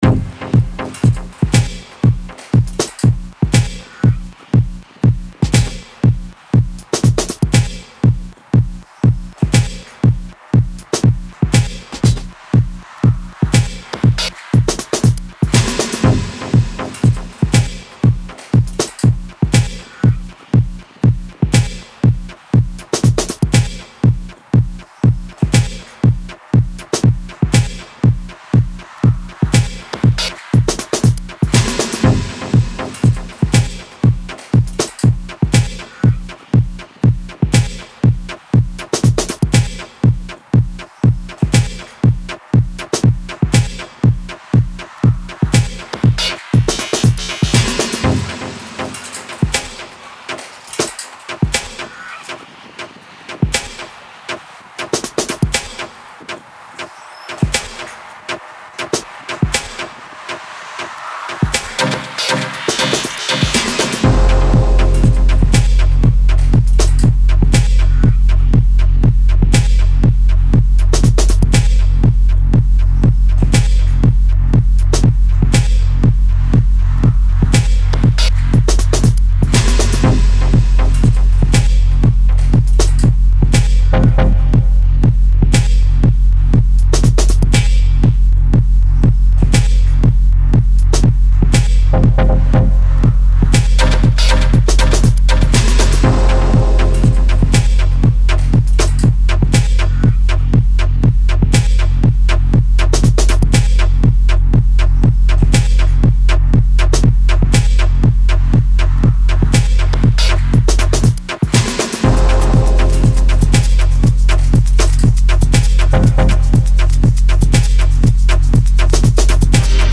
hypnotic Dub Techno